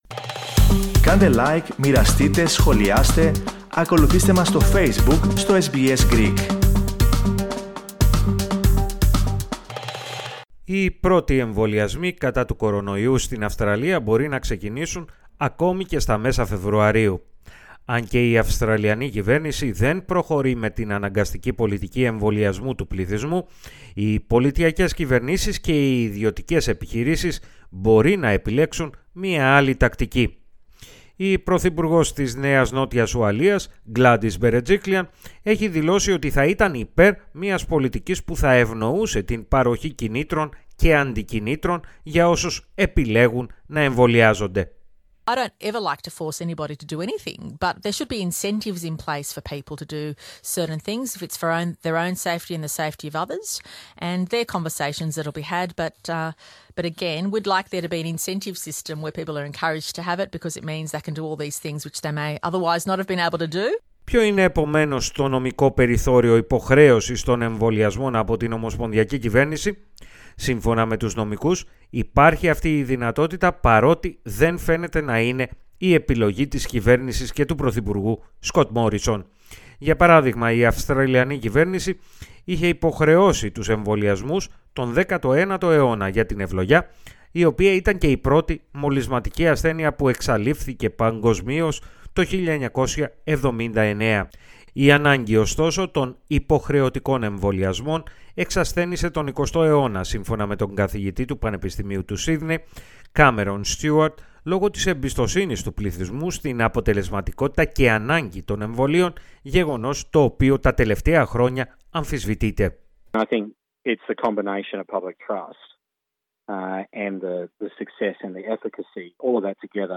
Η Αυστραλιανή κυβέρνηση, έχει καταστήσει σαφές ότι δεν πρόκειται να κάνει υποχρεωτικούς τους εμβολιασμούς για τη νόσο COVID-19. Νομικοί, ωστόσο, επισημαίνουν ότι αυτό δεν μπορεί να υποχρεώσει επιχειρήσεις από το να αρνούνται την παροχή υπηρεσιών, σε όσους δεν είναι εμβολιασμένοι. Περισσότερα, ακούστε στην αναφορά